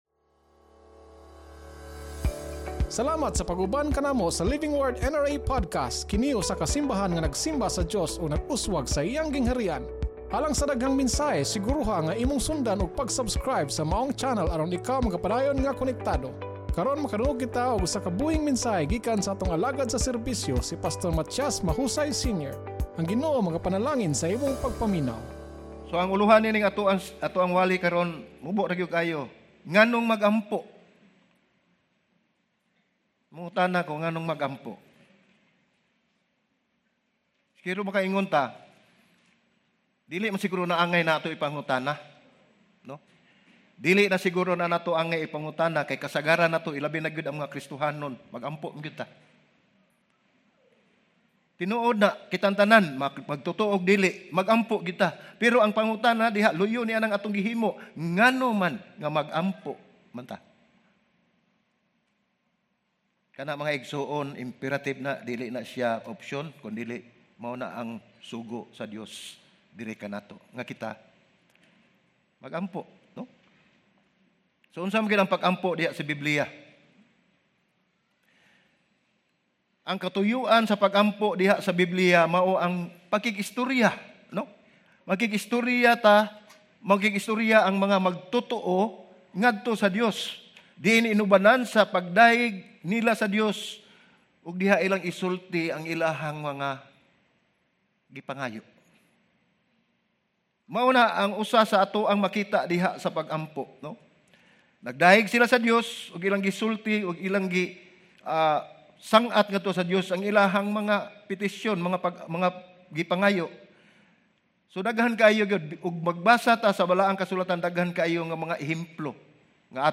Sermon Title: NGANONG MAG-AMPO